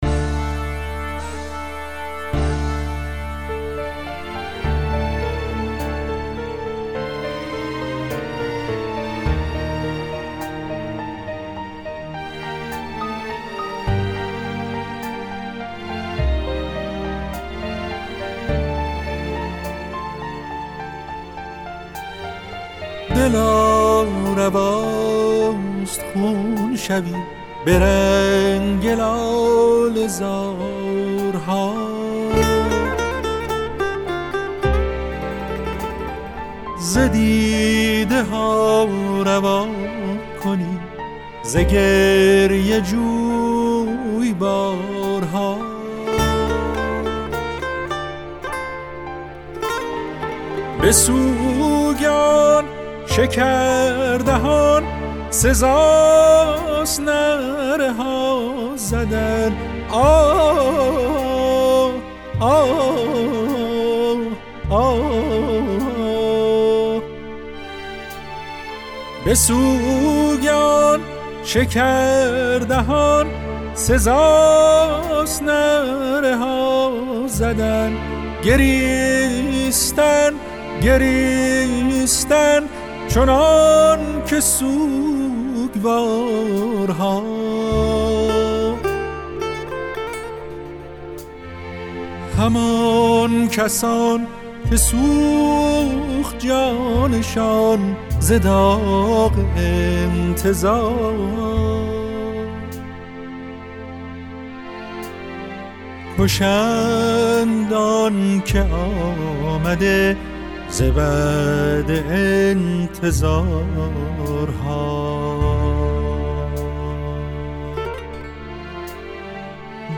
دلا رواست خون شوی - سوگ سرودی در شهادت حضرت اعلی | تعالیم و عقاید آئین بهائی
dela ravast-song.mp3